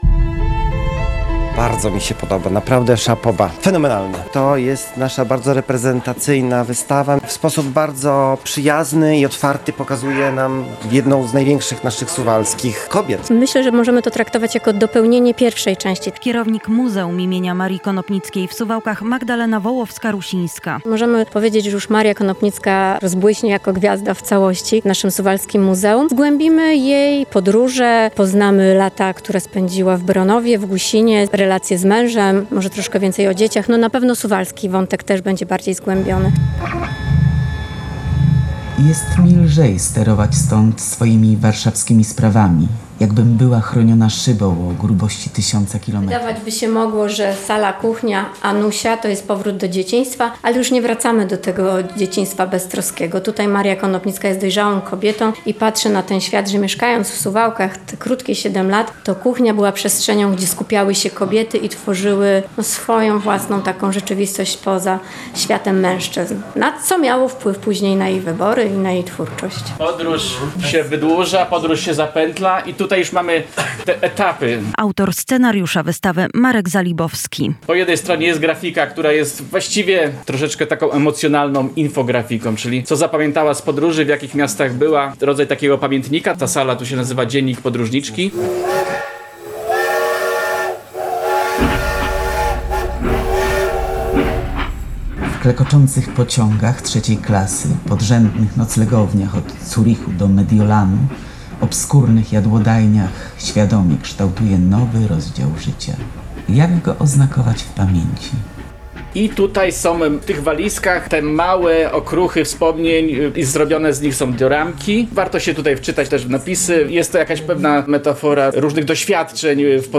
Otwarcie wystawy "W drodze" w Muzeum im. Marii Konopnickiej w Suwałkach